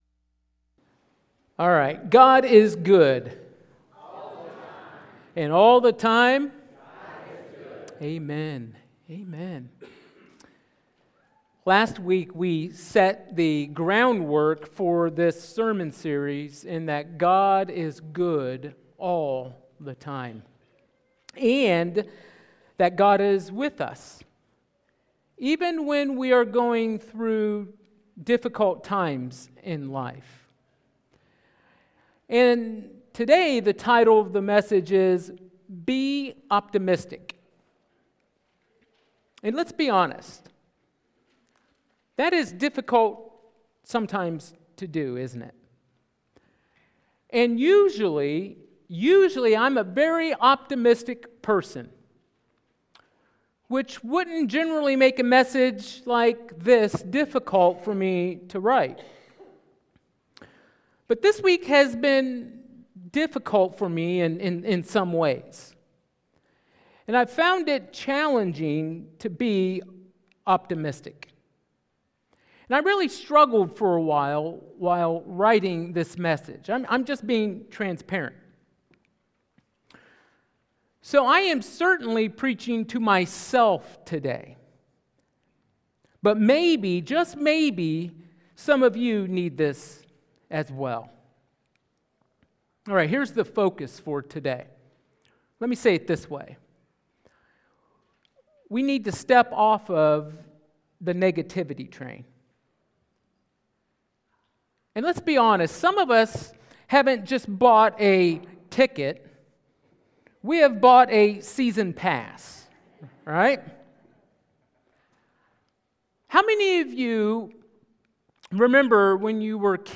Sermons | Nappanee First Brethren Church